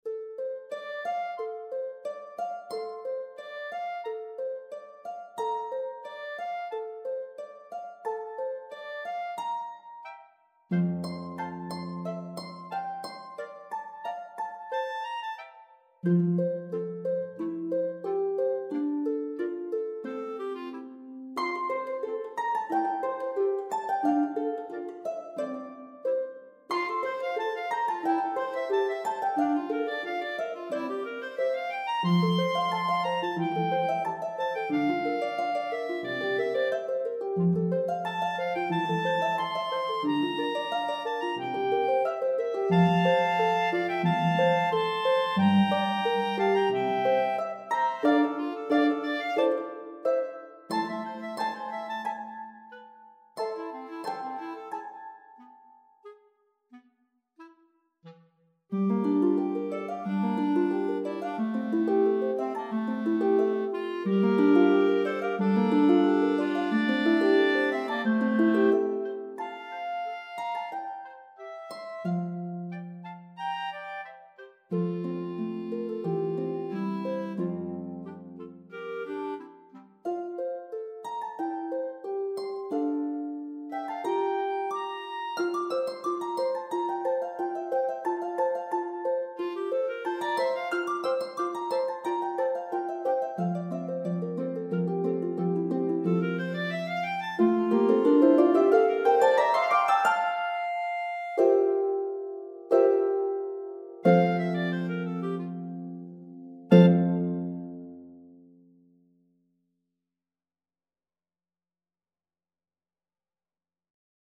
for lever or pedal harp and two B-flat clarinets